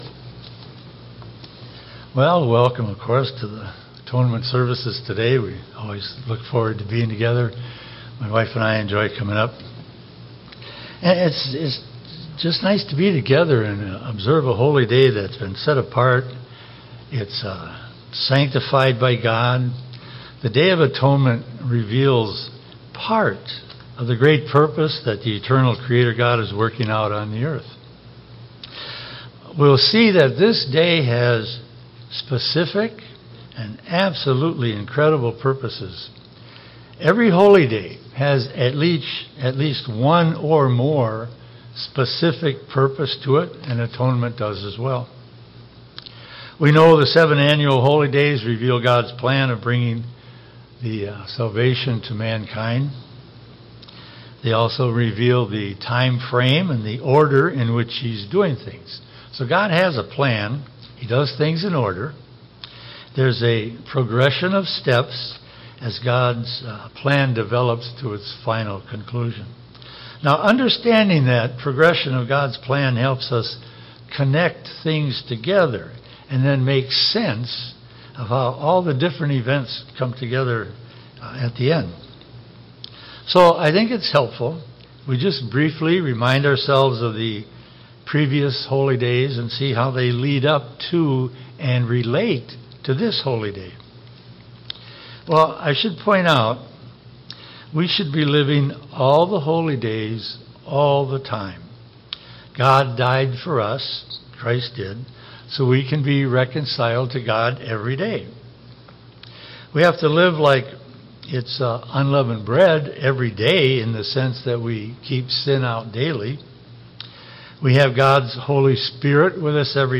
Sermons
Given in Eau Claire, WI